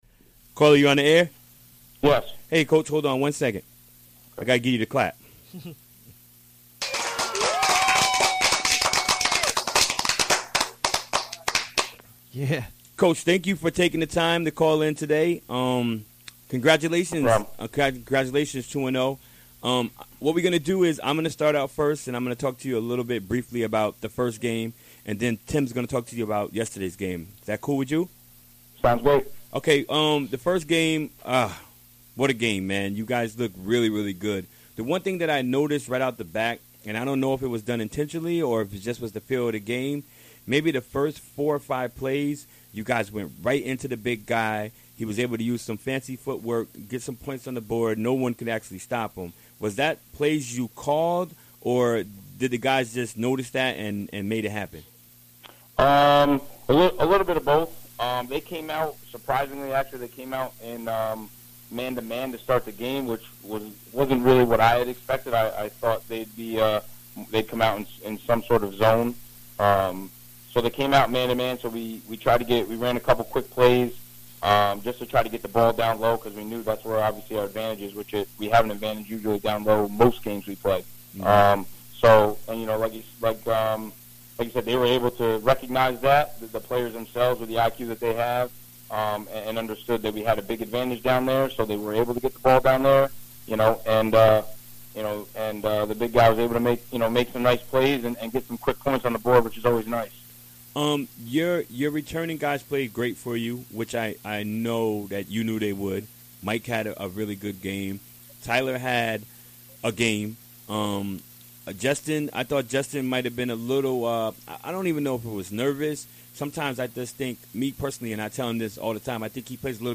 Recorded during the WGXC Afternoon Show Wednesday, December 7, 2016.